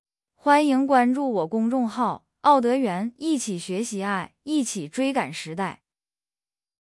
正常语速mp3格式:
OddTTS-kokoro-正常速度mp3格式.mp3